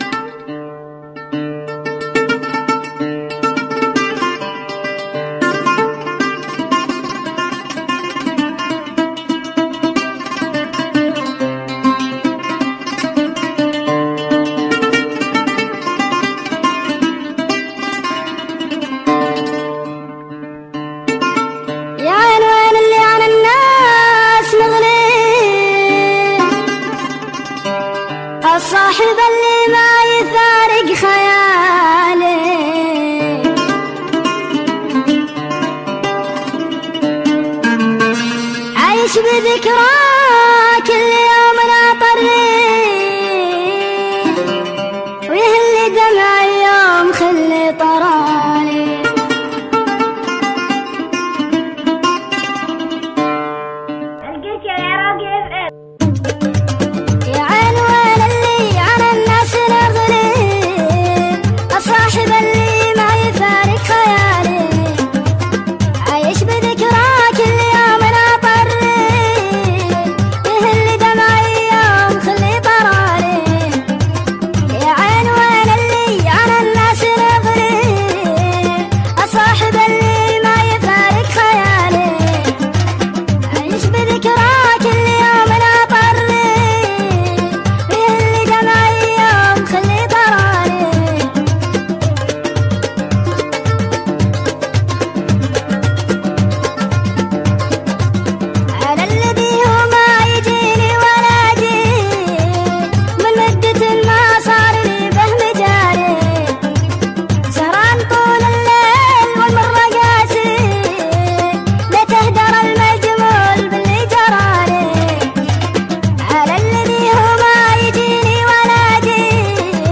اغاني شعبي